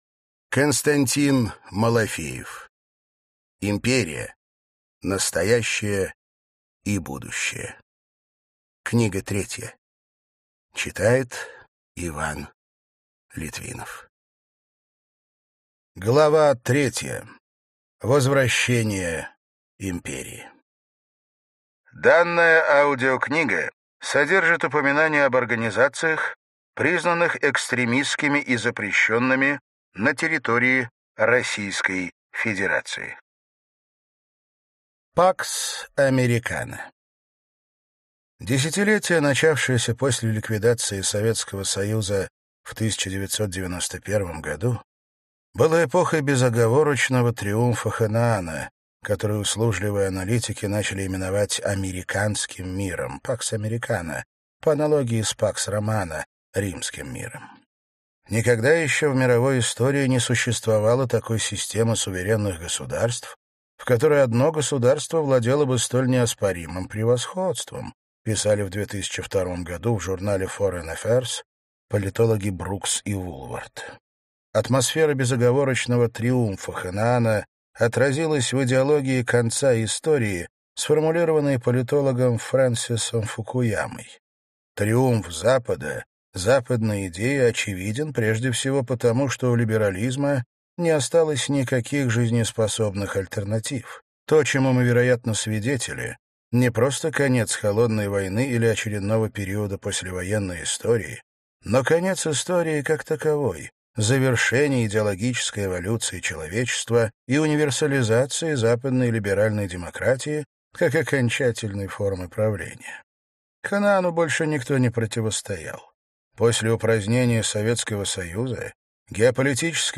Аудиокнига Империя. Настоящее и будущее. Книга 3. Часть 3 | Библиотека аудиокниг